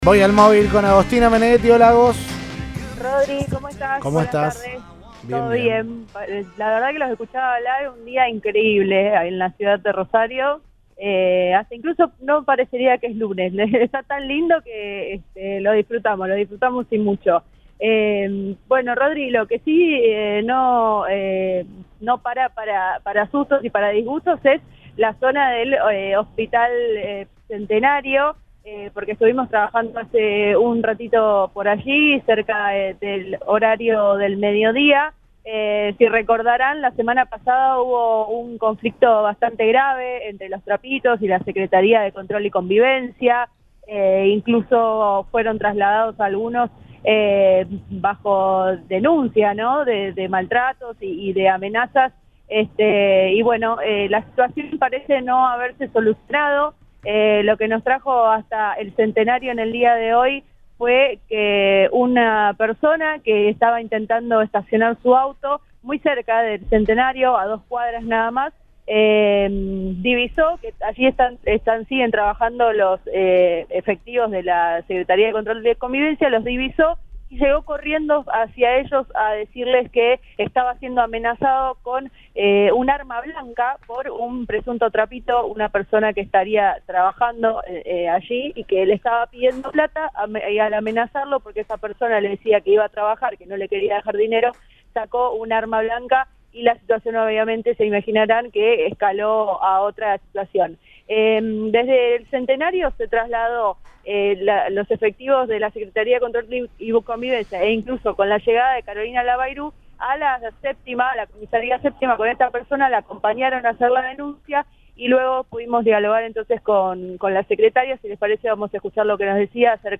En tanto, este lunes, según contó la secretaria de Control y Convivencia de Rosario, Carolina Labayru, al móvil de Cadena 3 Rosario, en el marco de controles que realizan “hace una semana” en Francia y Urquiza “controlando que no estén trabajando los trapitos”, advirtieron “se acerca una persona con un cuchillo en su mano denunciando que un trapito de la zona de Tucumán 2900, a dos cuadras del lugar, le quiso robar y que quiso cobrarle el uso del espacio público”.